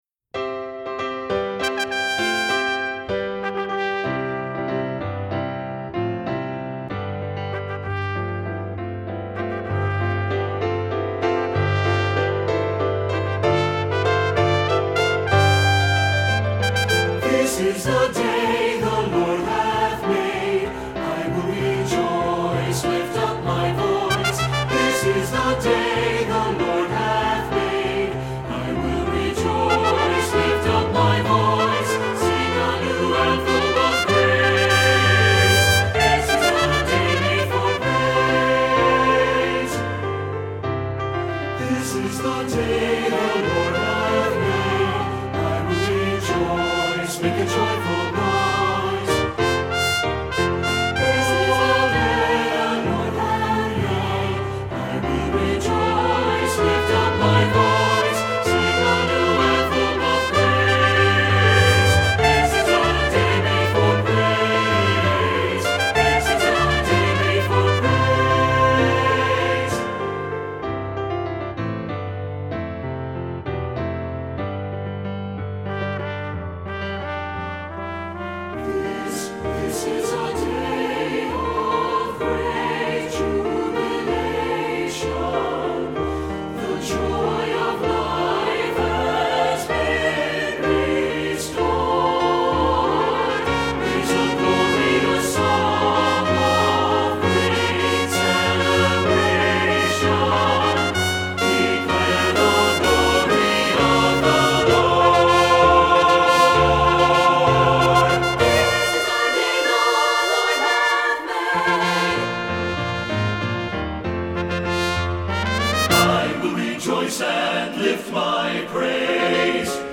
Voicing: SATB and Trumpet